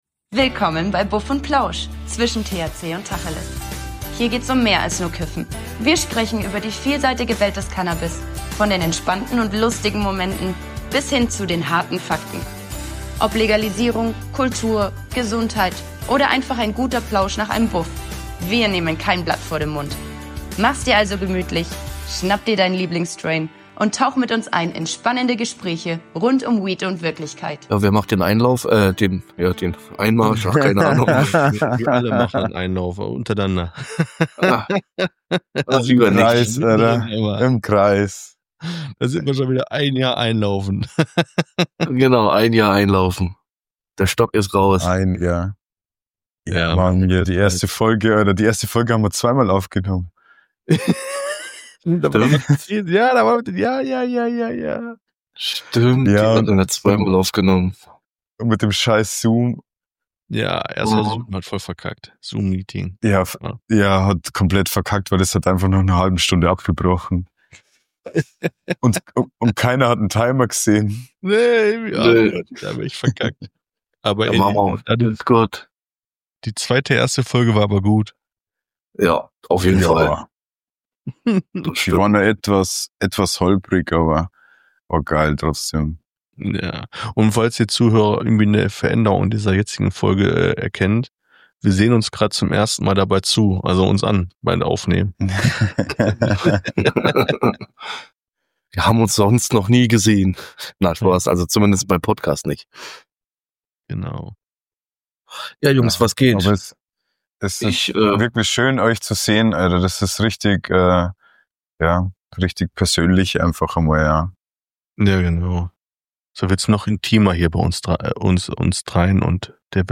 Danke an alle, die uns drei Jungs seit Tag eins beim Labern zuhören und uns regelmäßig in ihre Ohrmuscheln lassen.